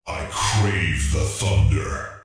Subject description: Some highly electronic hero unit voice resources!
These voices were self generated by me, and I carried out a series of complex follow-up work to make them highly electronic and magnetic.